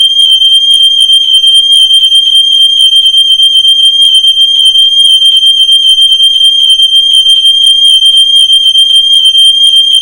Aus diesem Grund haben wir die Warnmelder Alarme zahlreicher aktueller Modelle für Sie aufgezeichnet.
gira-dual-q-funkrauchmelder-alarm.mp3